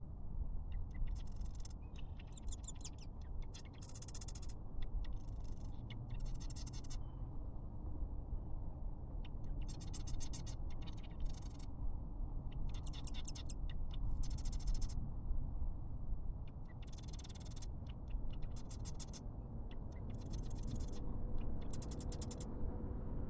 Here's a little snippet from one of the marsh wrens on my walk yesterday, who was ...